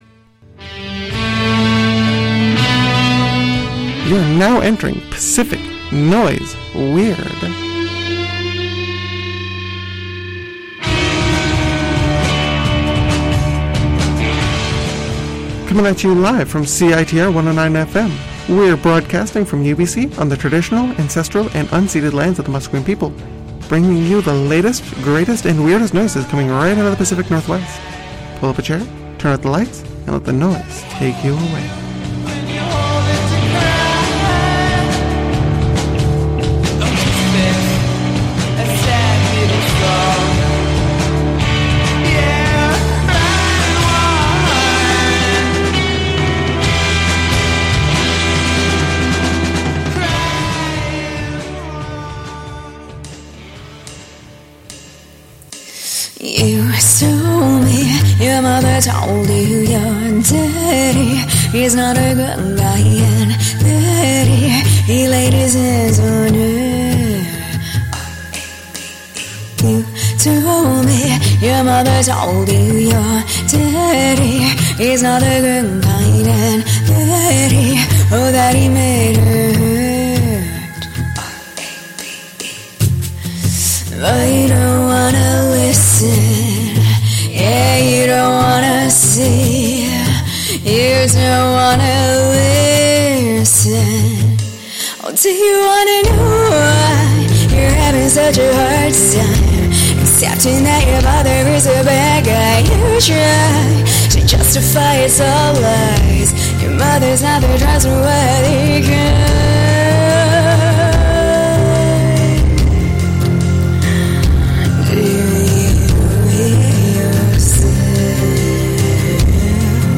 Grungy and downtempo